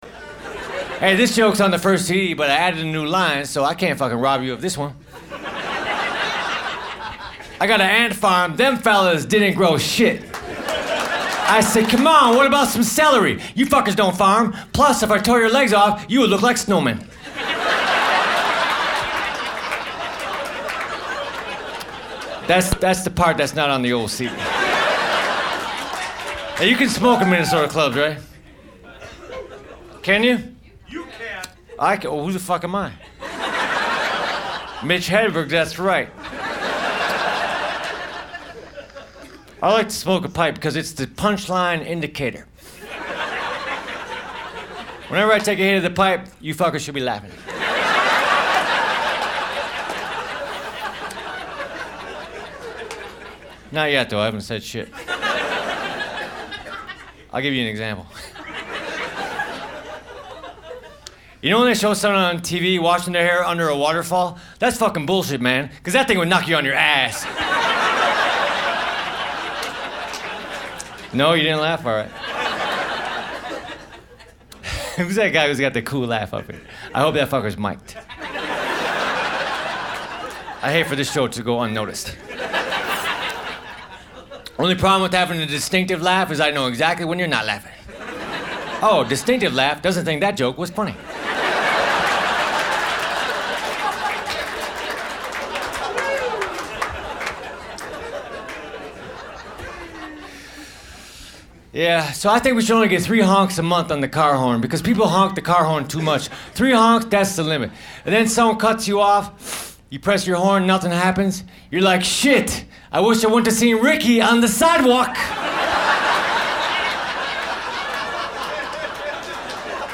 His name was Mitch Hedberg. Click his picture to hear 2 minutes of his routine.
And FYI, he says a few swear words.